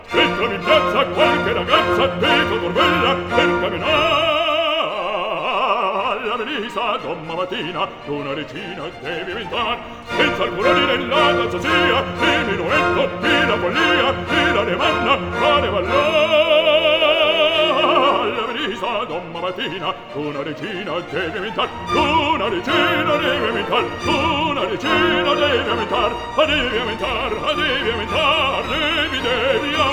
Classical Opera
Жанр: Классика